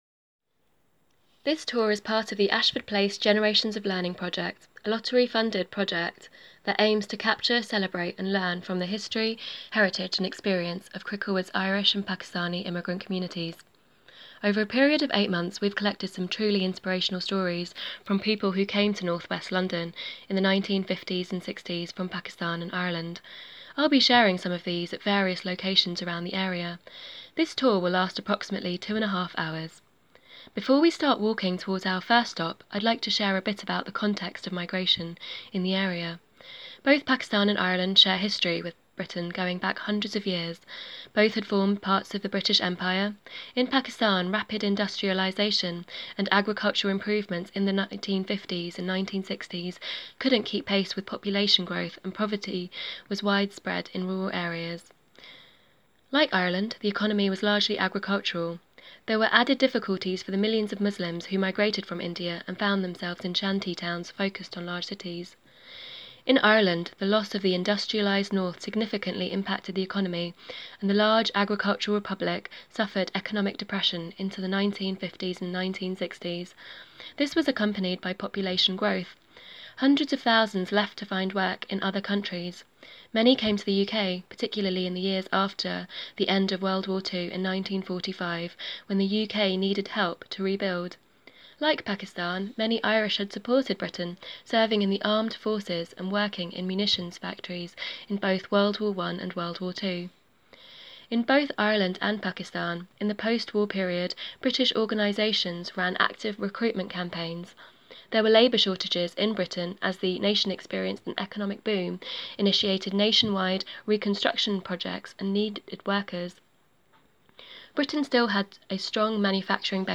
These walking tours combines the stories of the Elders with places of significance to the Irish and Pakistani communities. If you would like to try the tour yourself, you can download the Audio Guide and Map.
CricklewoodMemoryTour.mp3